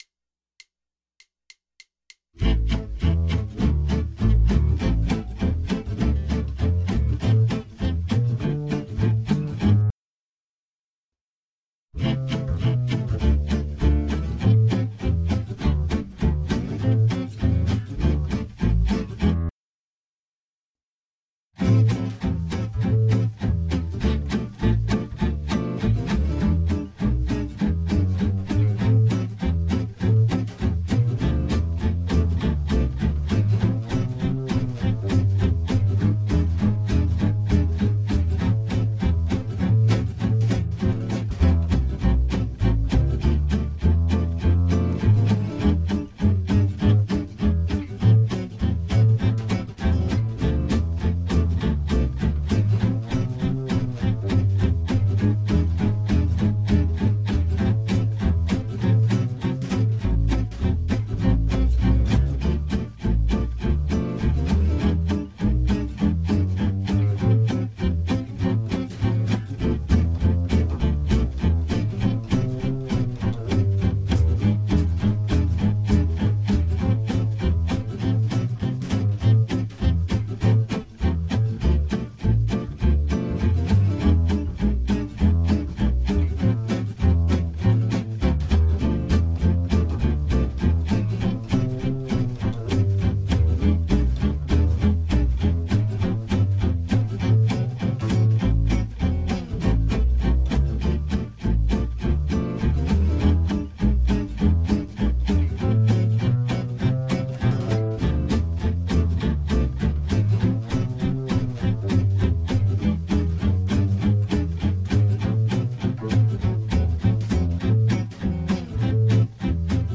la música celta y el gypsy jazz
Ejercicios con backtracks